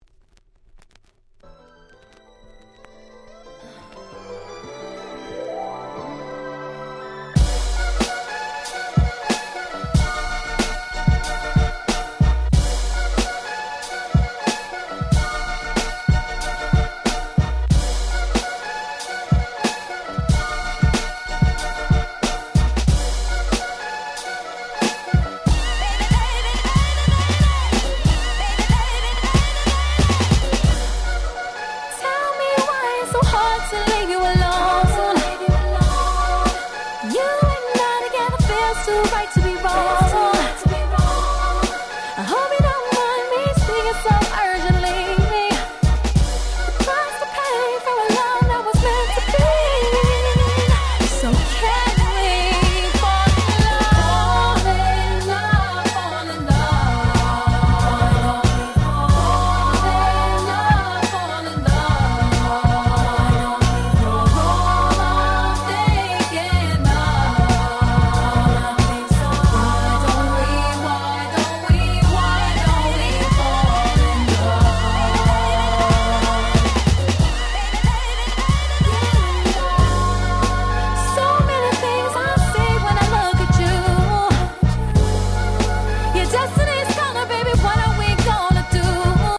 02' Smash Hit R&B !!
何て言うんでしょ、この『夕暮れ感』、最高に気持ち良いです。